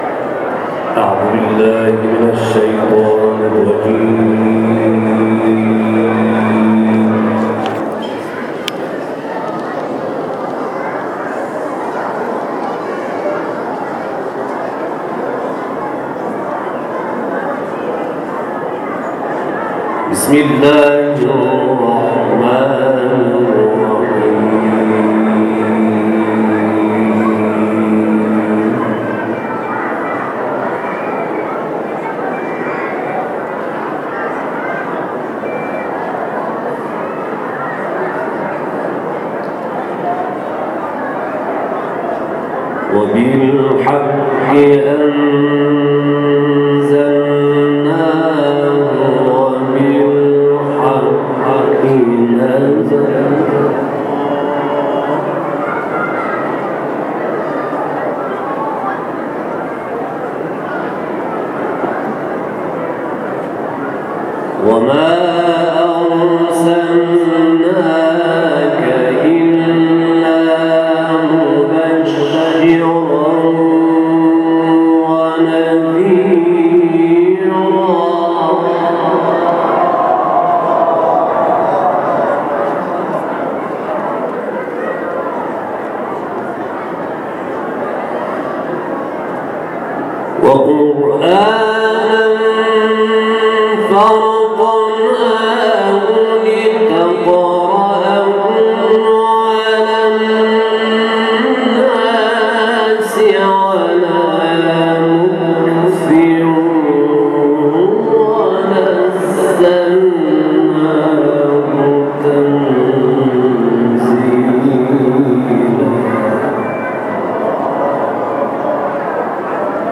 آرامگاه شهید مدرس کاشمر